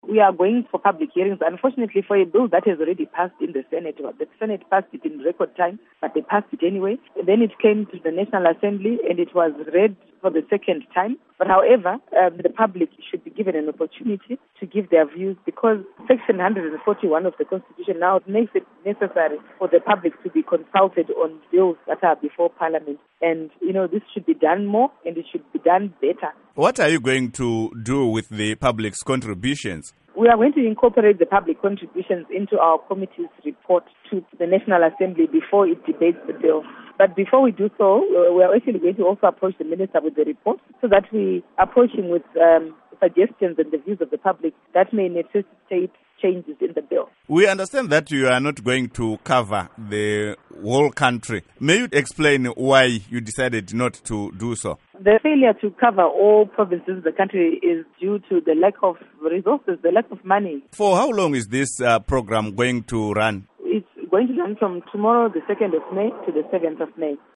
Interview With Jessie Majome